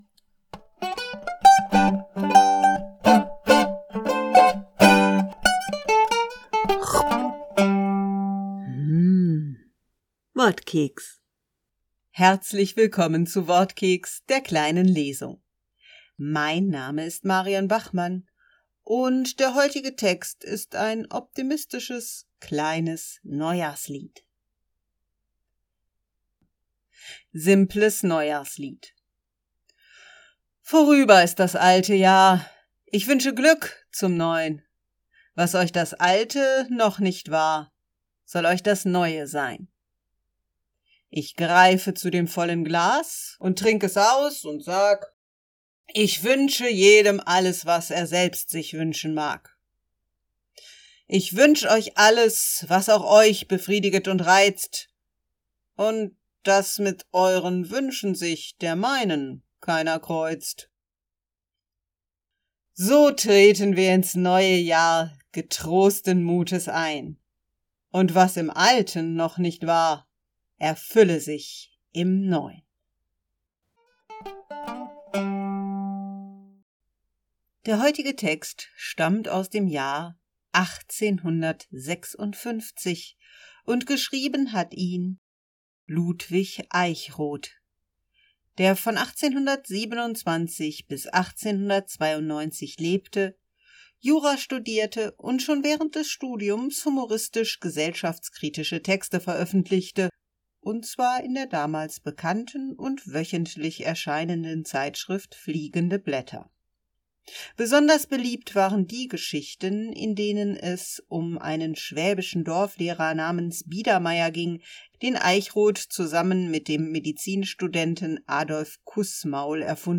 Wortkeks - die kleine Lesung